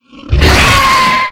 flesh_aggressive_1.ogg